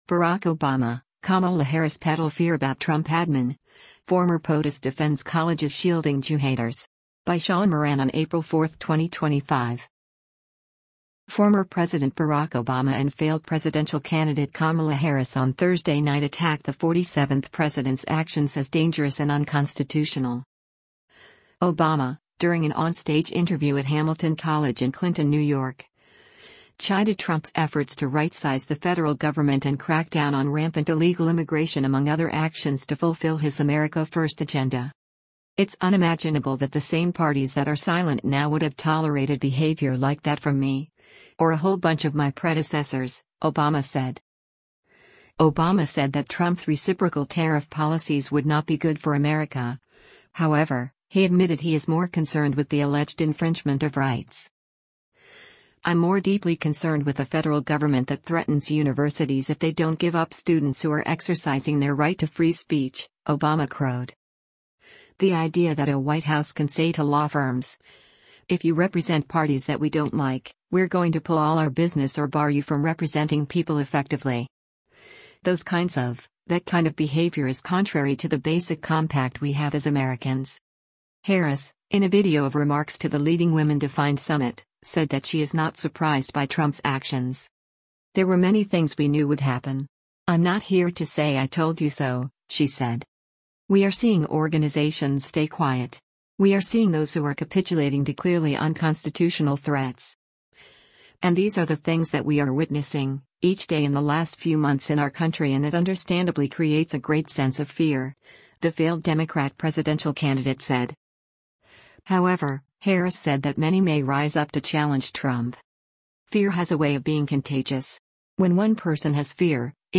Obama, during an on-stage interview at Hamilton College in Clinton, New York, chided Trump’s efforts to right-size the federal government and crack down on rampant illegal immigration among other actions to fulfill his “America First” agenda.
Harris, in a video of remarks to the Leading Women Defined Summit, said that she is not surprised by Trump’s actions.